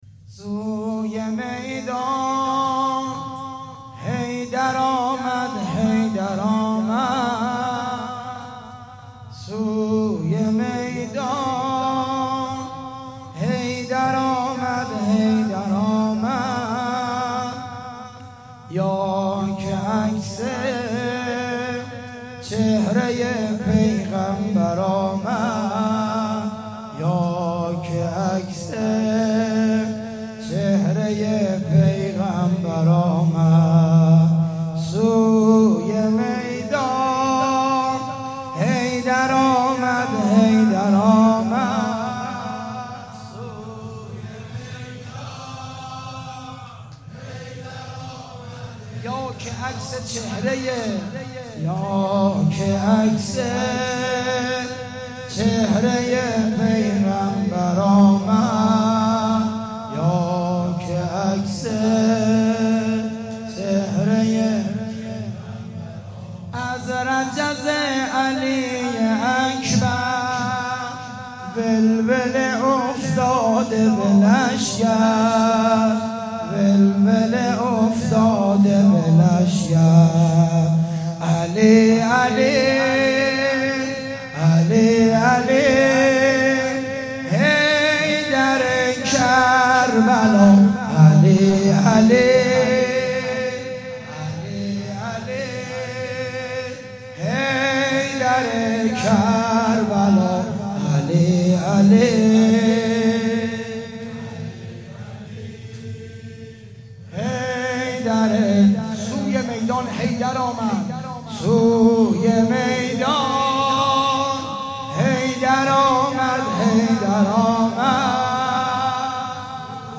تکیه ی چهل اختران - قم
سبک زمینه
محرم 97 - قم المقدسه